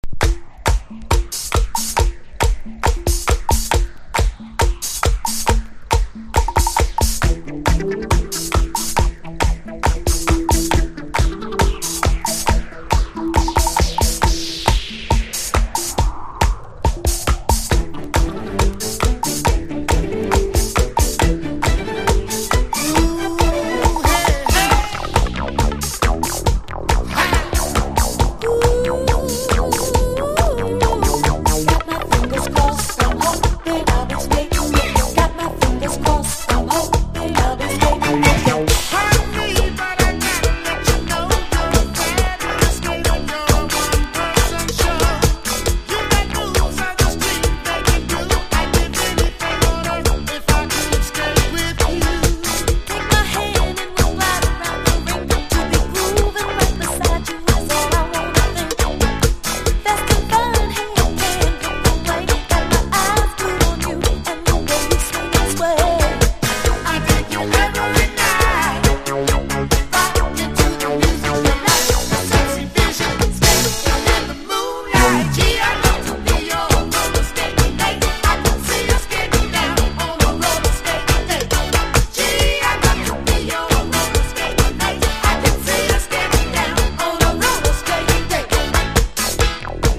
波打つシンセ・ベースが印象的なミュンヘン・ディスコの影響を感じさせる79年作！！（オリジナル盤）